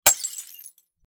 Glass Breaking 04
Glass_breaking_04.mp3